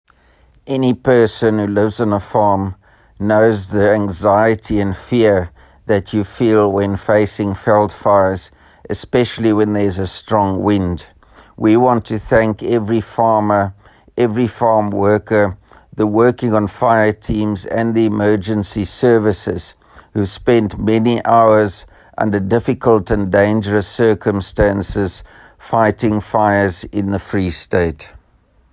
Issued by Roy Jankielsohn – DA Free State Premier Candidate
Afrikaans soundbites by Roy Jankielsohn MPL as well as images here and here